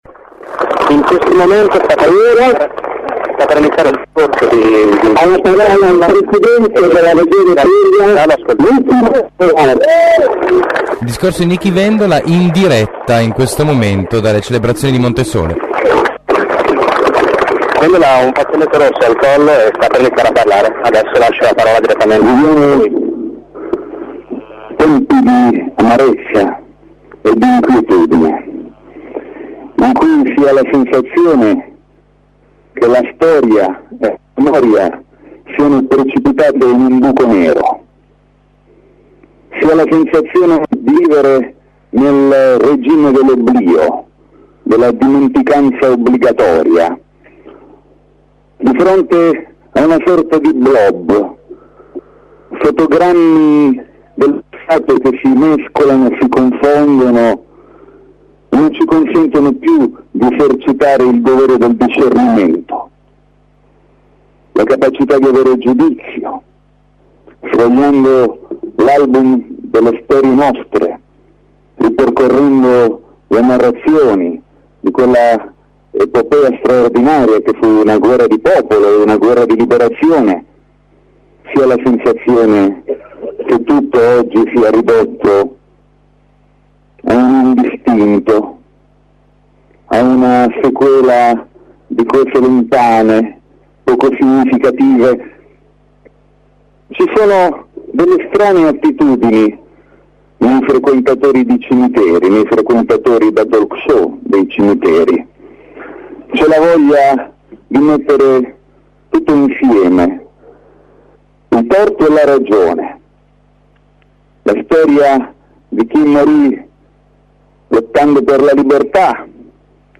Oltre tremila persone a Monte Sole per le Celebrazioni della Liberazione.